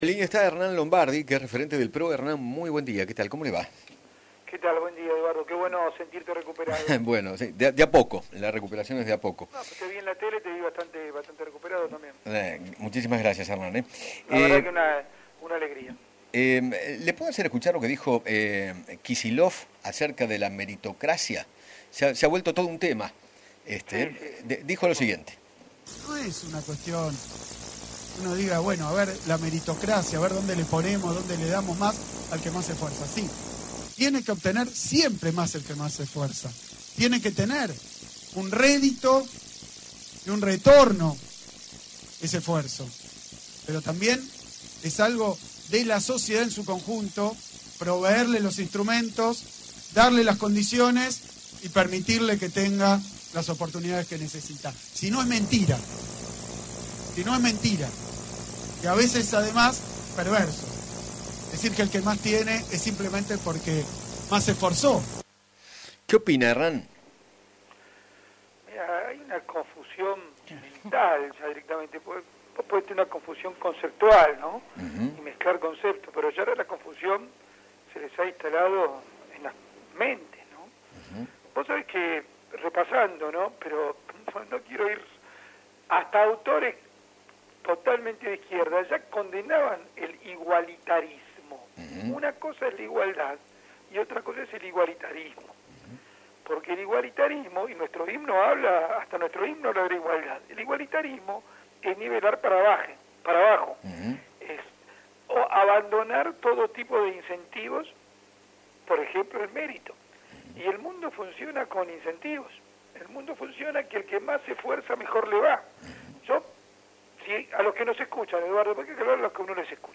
Hernán Lombardi, secretario de Medios de Mauricio Macri y uno de los referentes del espacio Cambiemos, dialogó con Eduardo Feinmann sobre los mensajes de Alberto Fernández y Axel Kicillof acerca de la meritocracia y sostuvo que “el mérito es quien se rompe el lomo todos los días”.
(Audio de Axel Kicillof)